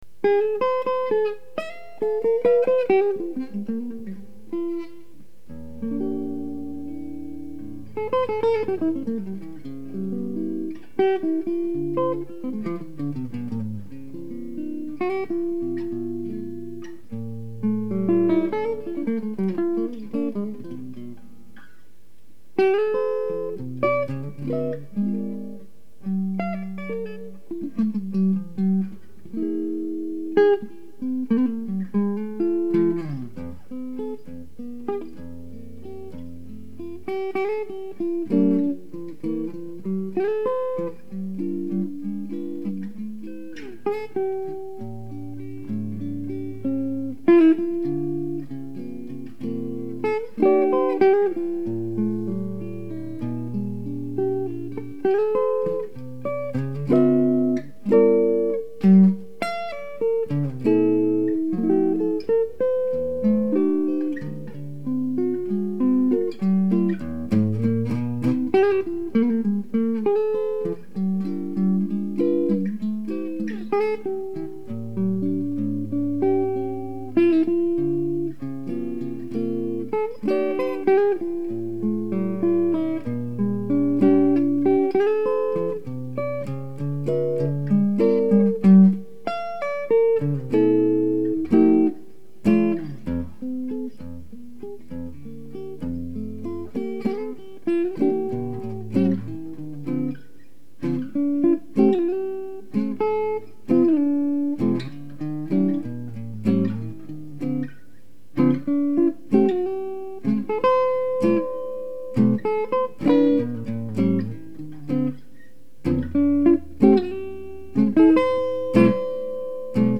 Chitarra solo